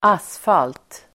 Ladda ner uttalet
Uttal: [²'as:fal:t]